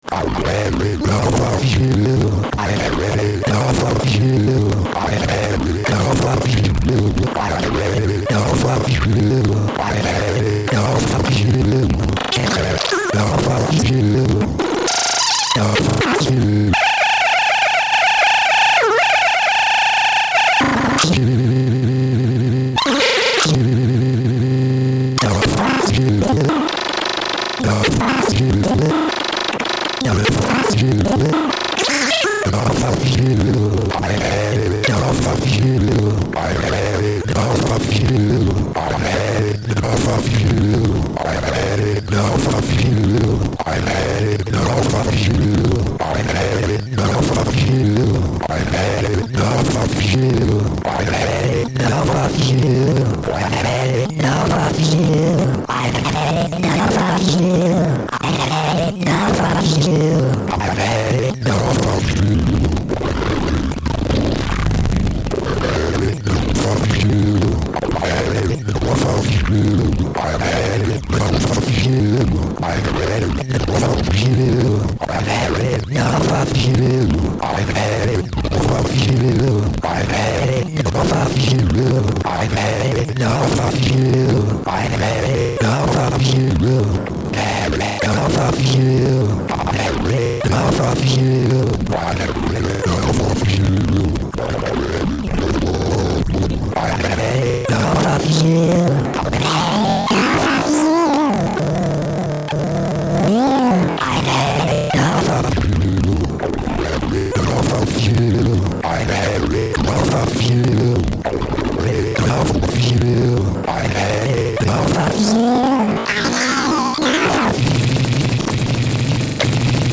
A pitch matching/shaping/warping 14 hp module using granular technology.
In this second sound example, we will switch the inputs around, so the voice will be pitch-matched to the wave.
By adjusting the controls, some really weird granular effects are obtained: